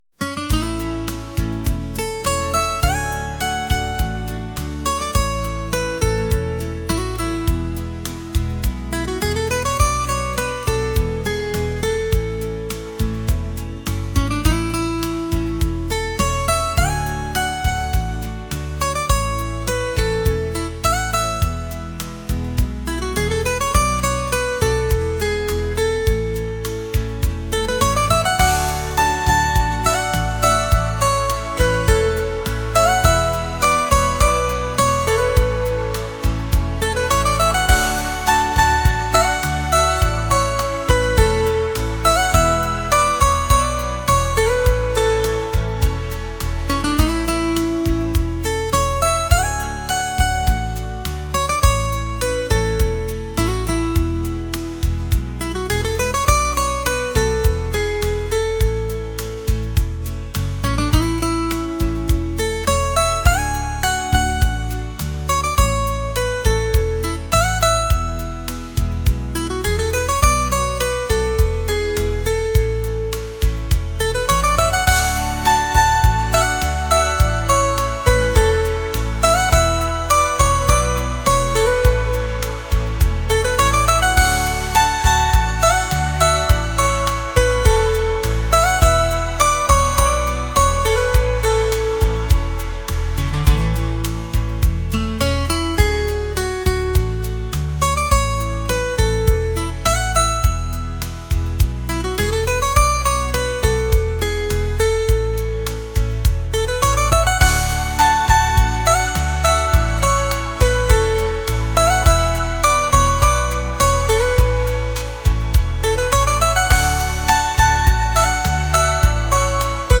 pop | acoustic | lofi & chill beats